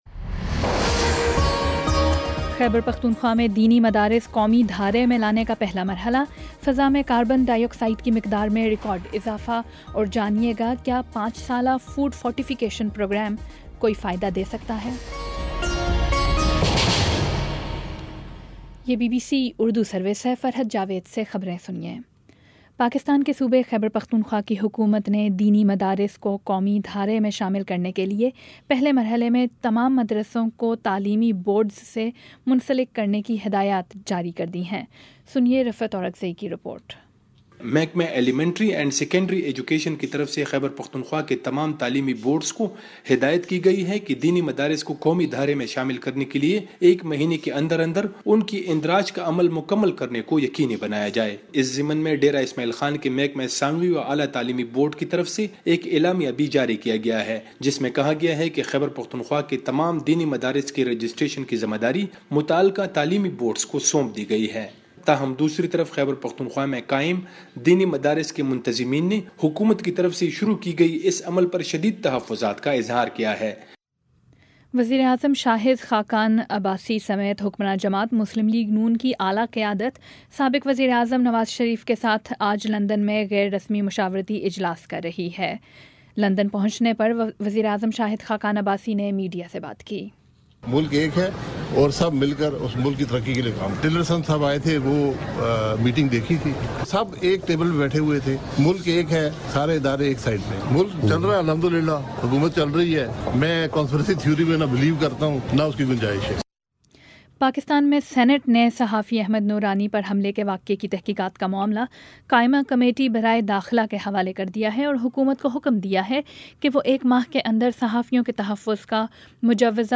اکتوبر 30 : شام چھ بجے کا نیوز بُلیٹن